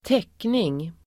Uttal: [²t'ek:ning]